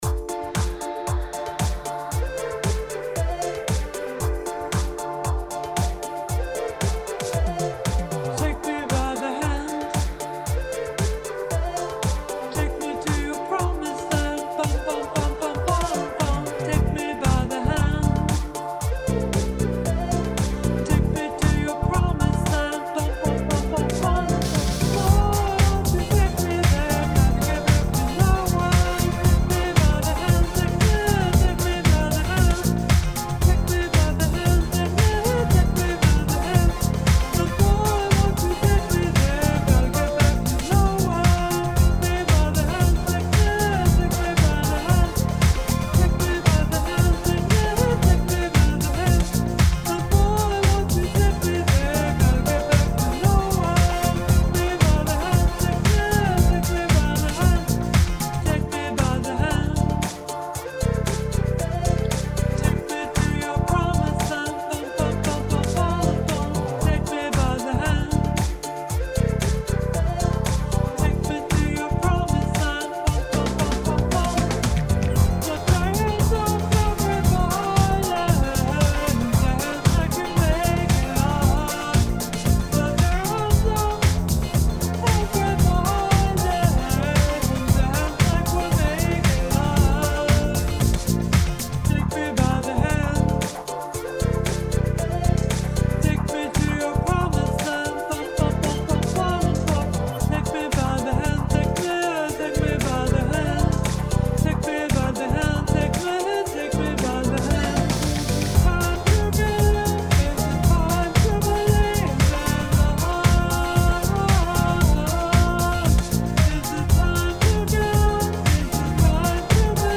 and is reggae pop rock!